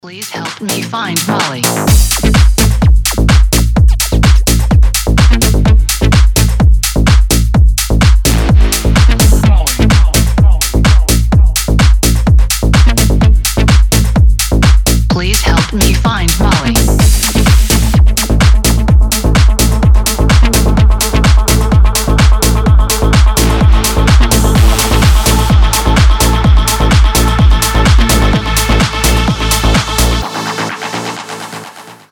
• Качество: 320, Stereo
ритмичные
Electronic
EDM
Tech House